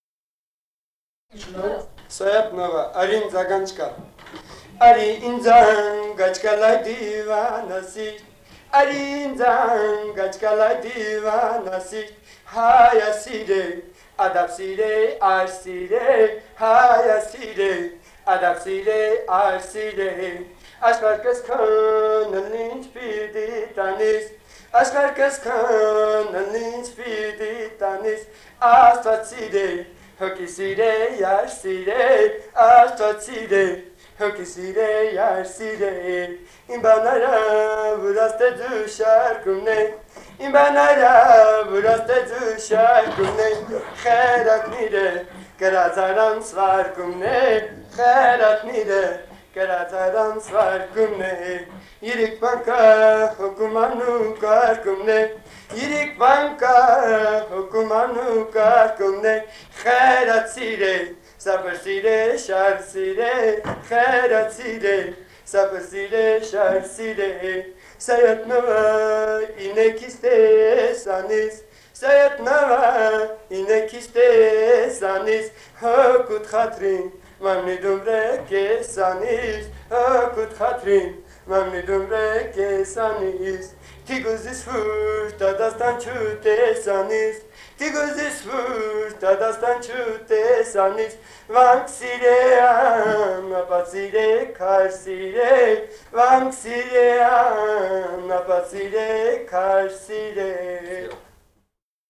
Этномузыкологические исследования и полевые материалы
«Ари индз ангач кал» (песня Саят Новы). Пел неизв. мужчина. Грузия, с. Дилифи, Ниноцминдский муниципалитет, 1971 г. И1310-22